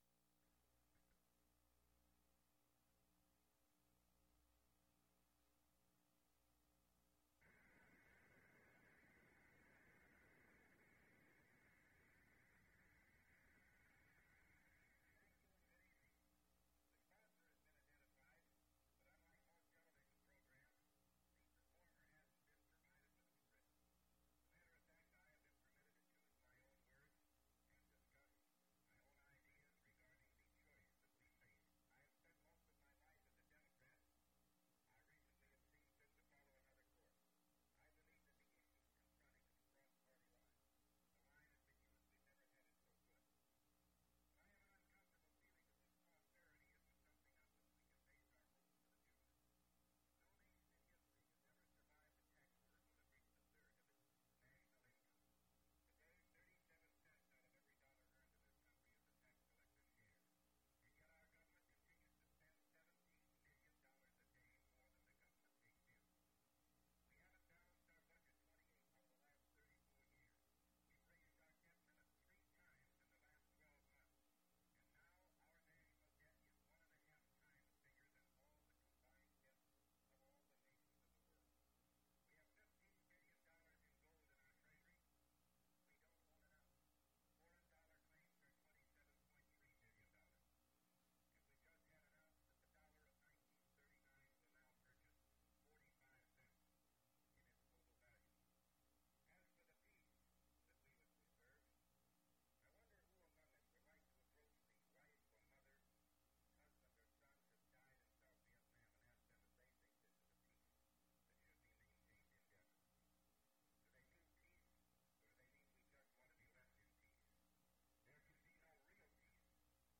Ronald Reagan's televised speech “A Time for Choosing”
Reel to Reel Audio Format (CD and WAV copy).